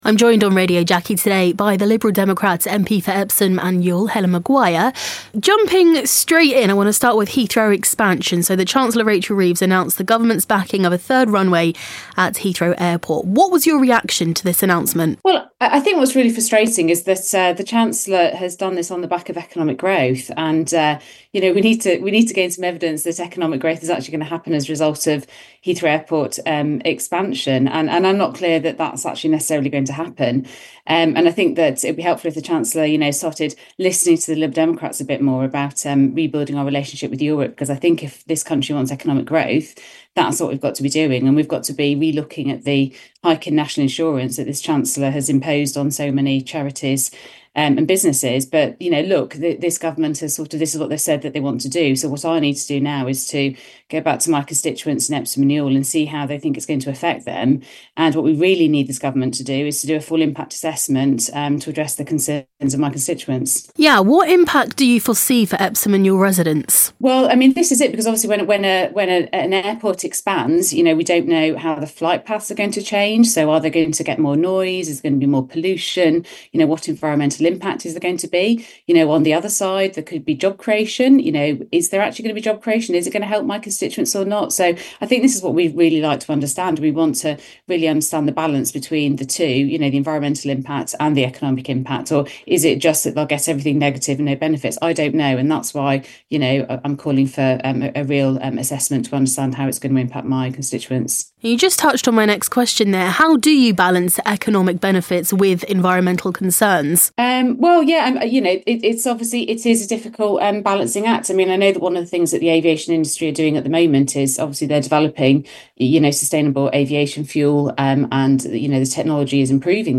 talks with Epsom's MP, Helen Maguire.